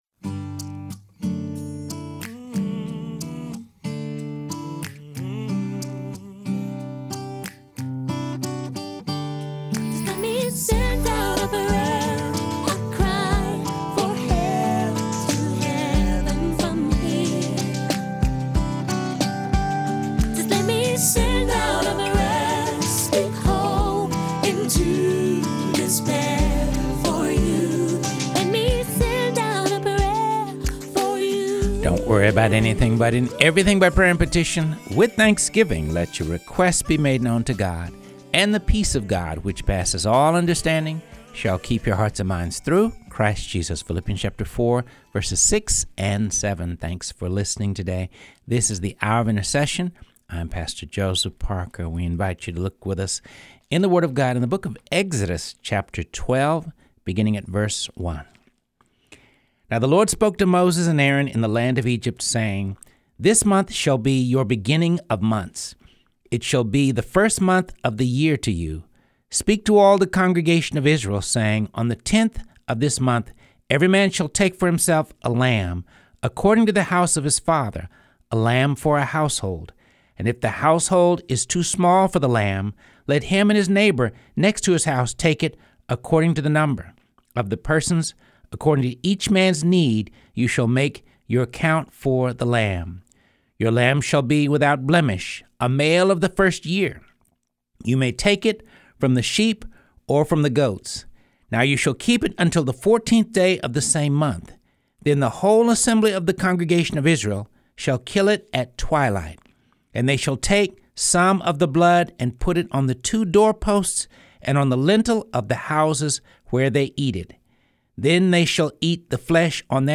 reads from the Bible